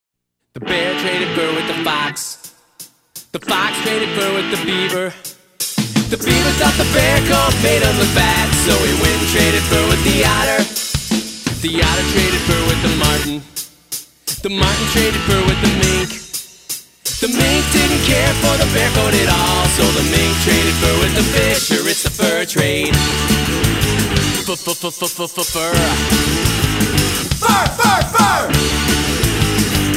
fun and quirky songs